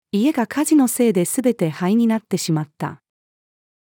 家が火事のせいで全て灰になってしまった。-female.mp3